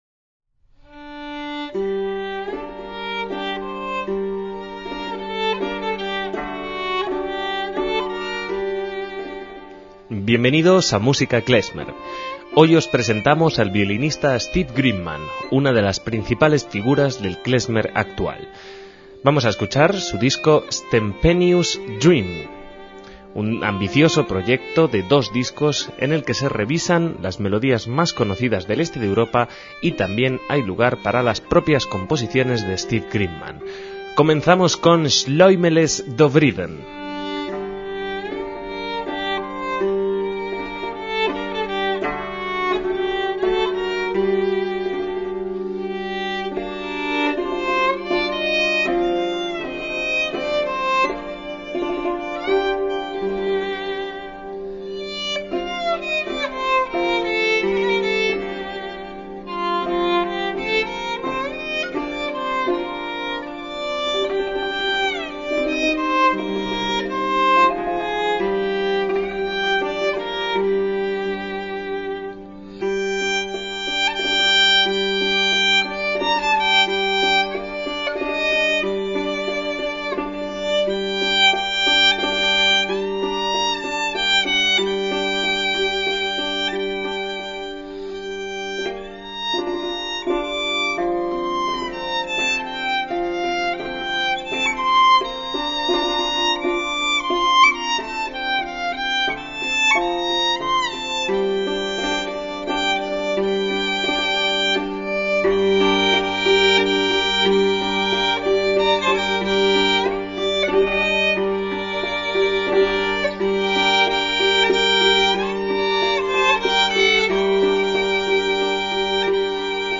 MÚSICA KLEZMER
el violinista
se rodea de notorios músicos klezmer
apasionantes duelos melódicos con el cimbalom